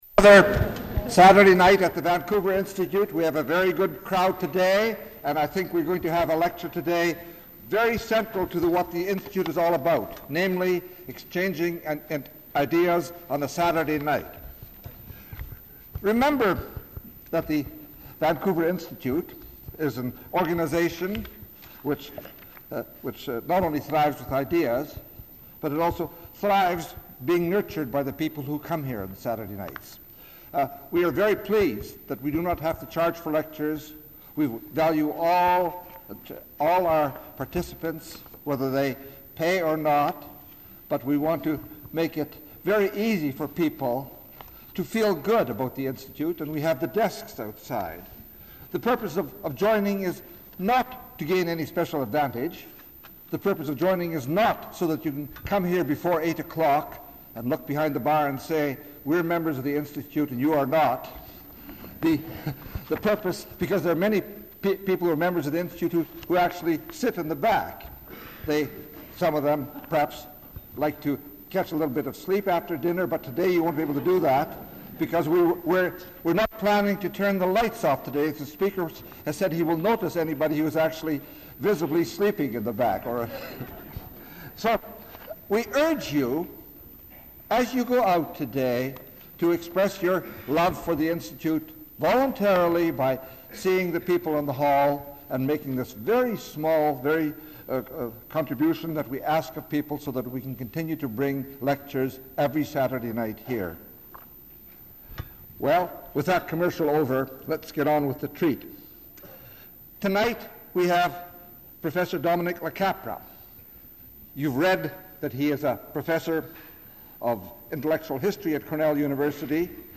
Item consists of a digitized copy of an audio recording of a Cecil and Ida Green Lecture delivered at the Vancouver Institute by Dominick LaCapra on October 19, 1996.